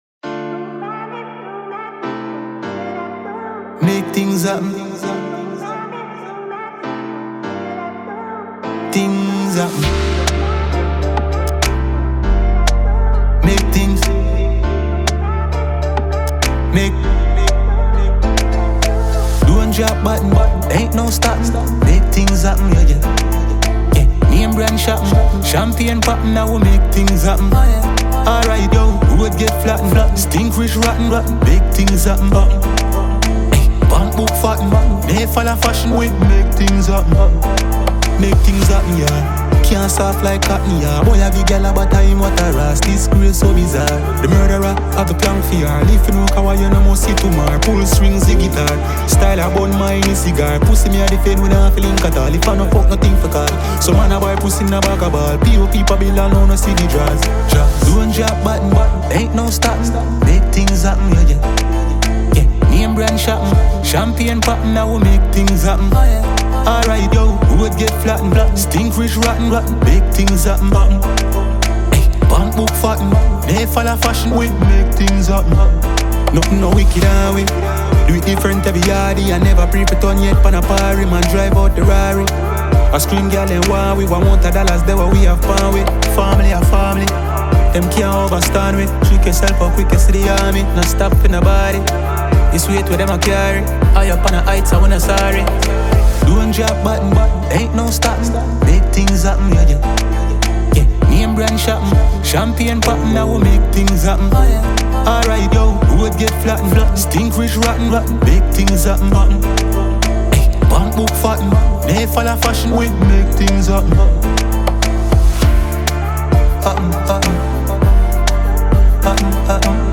Award-winning Jamaican dancehall musician